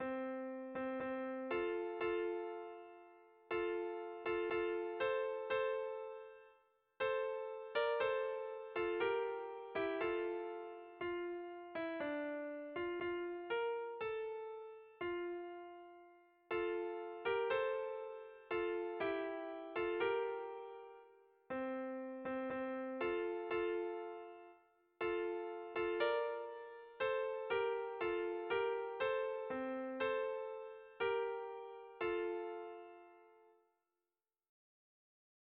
Erlijiozkoa
Seiko handia (hg) / Hiru puntuko handia (ip)
A-B-C